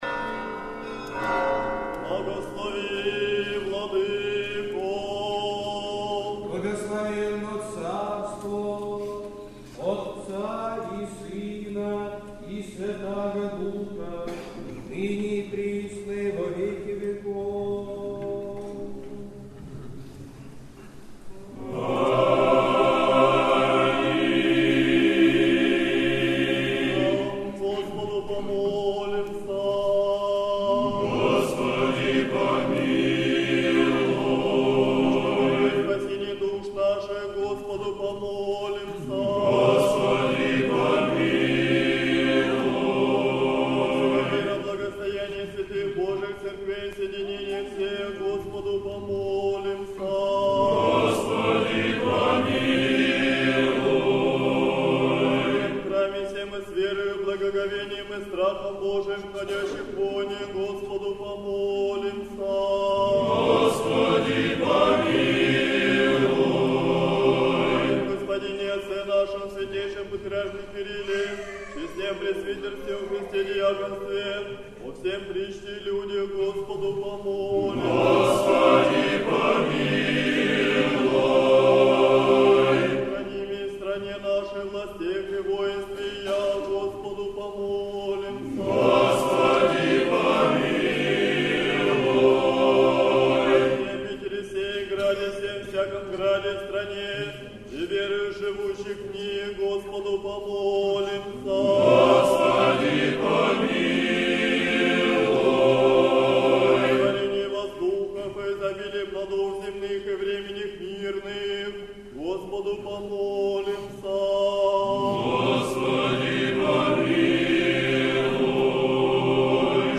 Сретенский монастырь. Божественная литургия. Хор Сретенской Духовной семинарии (MP3 файл.